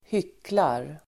Ladda ner uttalet
Uttal: [²h'yk:lar]